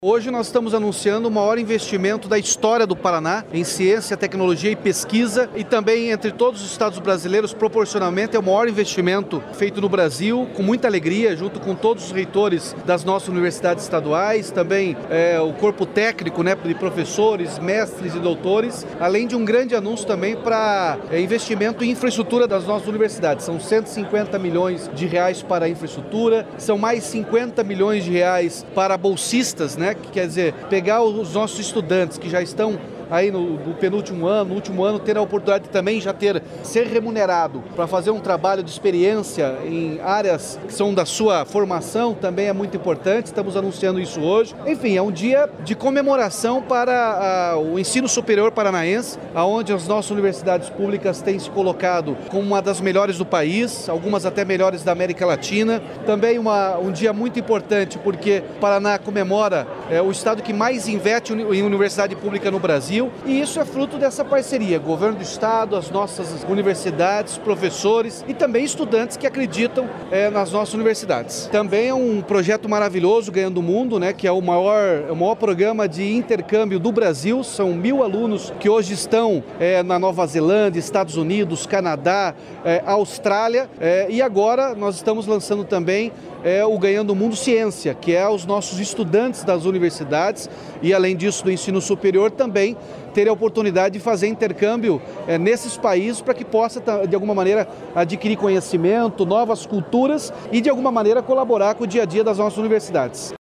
Sonora do governador Ratinho Junior sobre a liberação de R$ 212 milhões para obras em universidades estaduais e bolsas de estudo